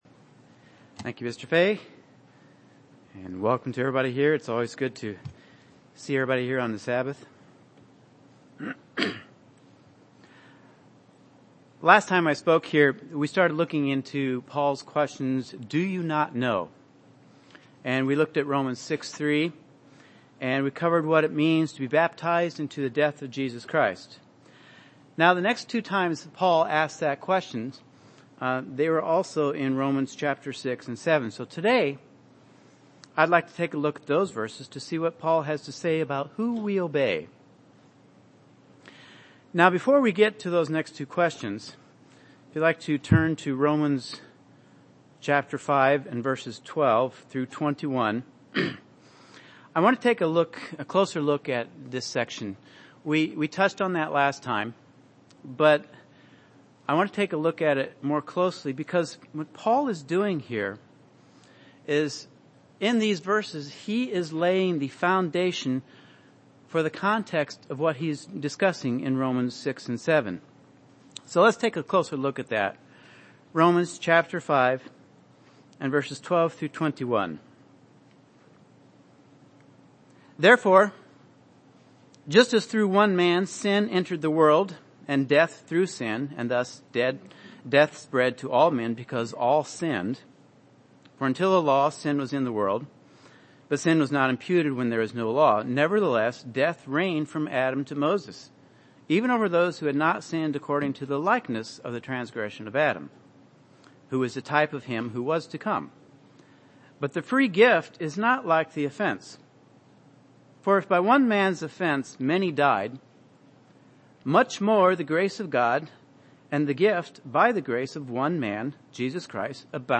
This sermon continues the study the questions, "Do you not know...?" that Paul asks in Romans 6 and 7. We'll examine his statements concerning our choices on what we obey after we are baptized; sin or God's law.